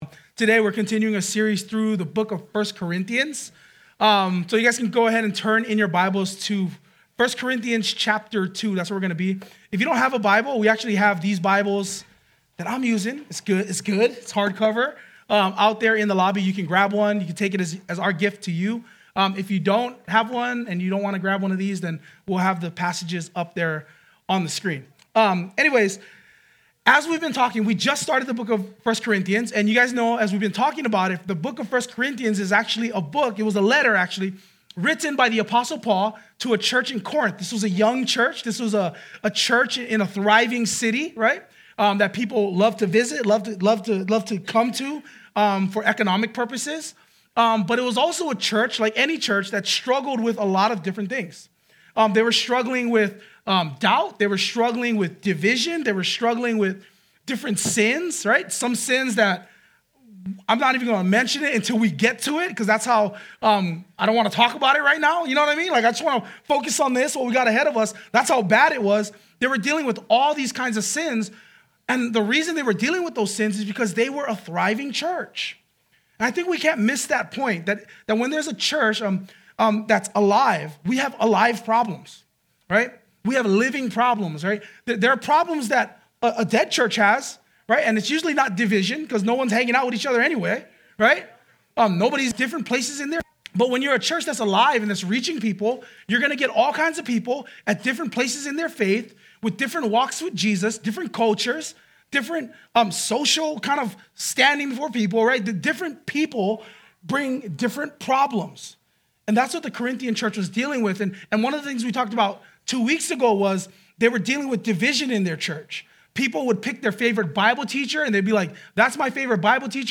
2023 Wisdom in Christ Preacher